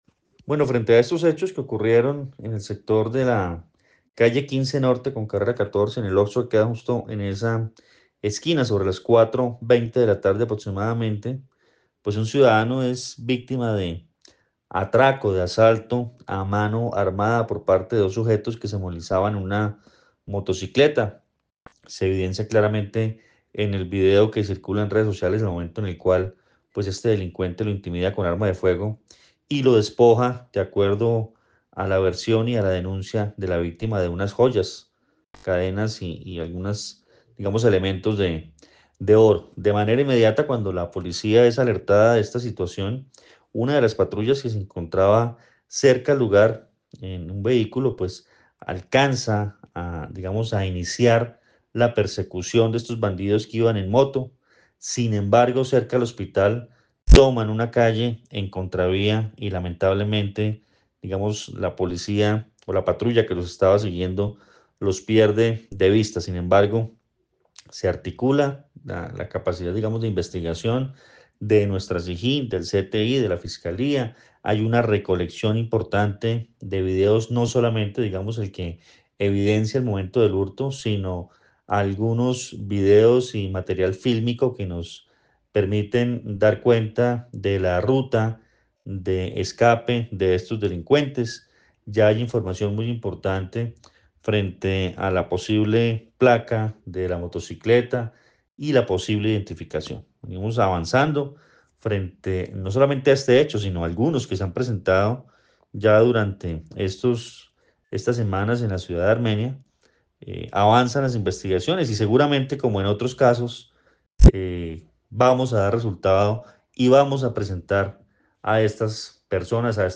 Coronel Luis Fernando Atuesta, comandante Policía, Quindío